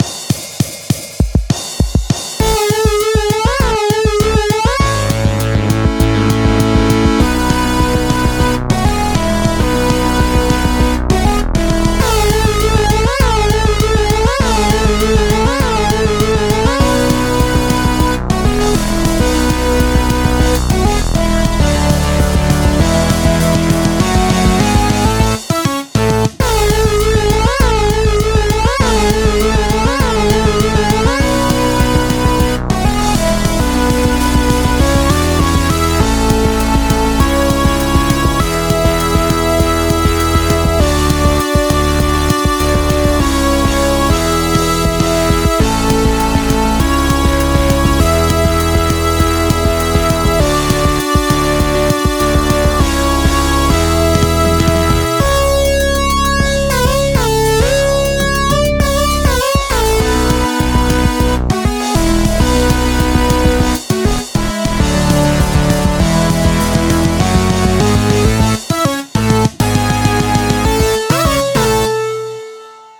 A fast punk-rock/metal like(?) track in A Major.